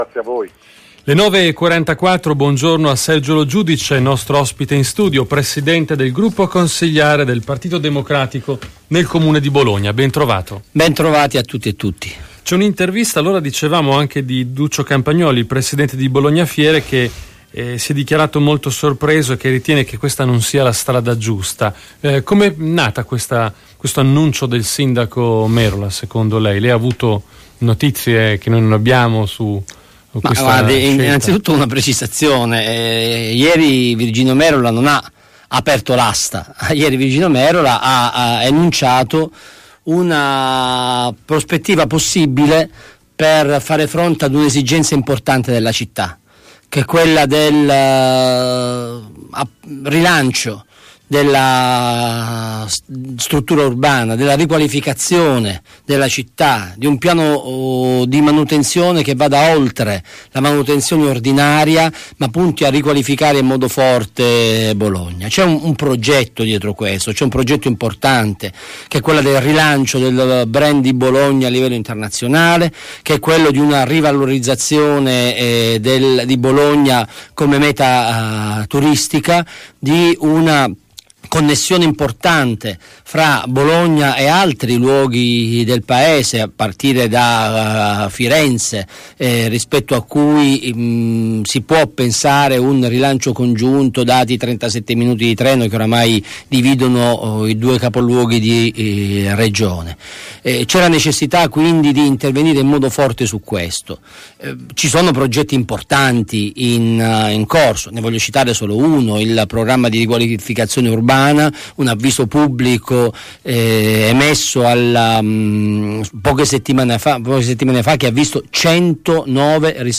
Il Presidente del gruppo consiliare PD Sergio Lo Giudice fornisce gli ultimi aggiornamenti sui principali temi d'attualità politica nell'intervista effettuata durante la trasmissione Detto tra noi